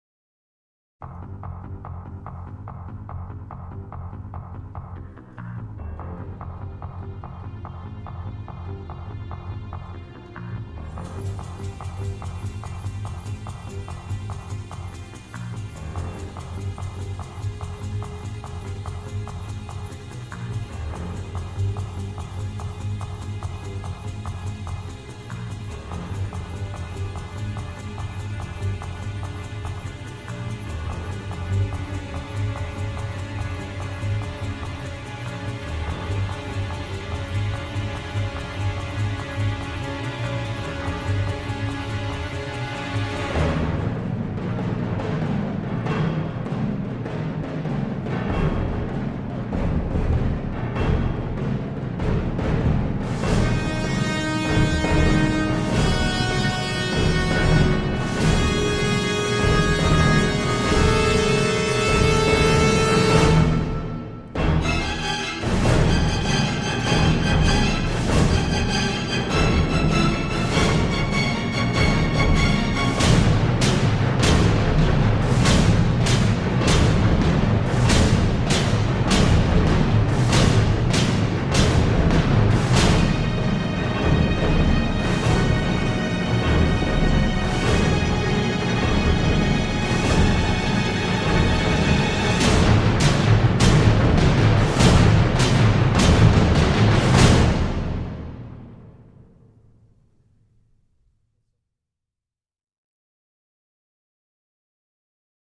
la trompette magistrale, les percussions cinglantes